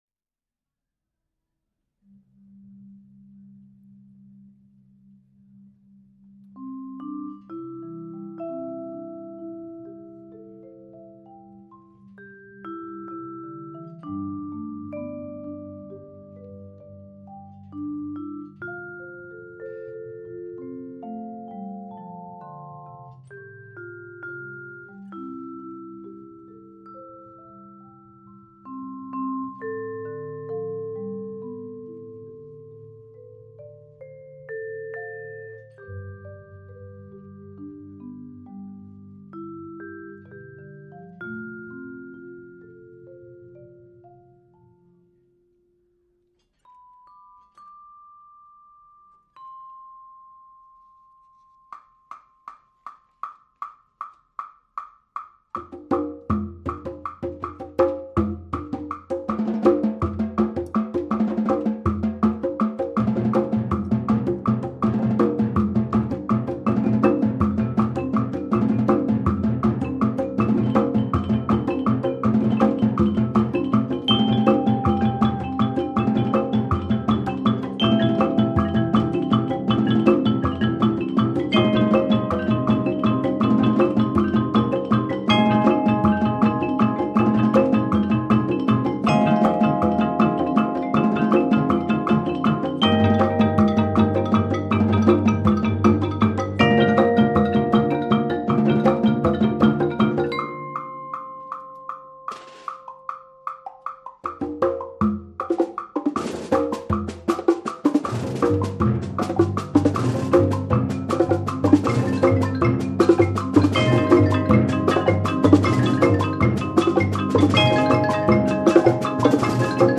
Genre: Percussion Ensemble
# of Players: 8
A fun, yet sentimental piece.
Vibraphone (3-octave)
Xylophone
Marimba 1 (4-octave)
Marimba 2 (4.3-octave)
Timpani
3 Woodblocks
Snare Drum
Bongos
Congas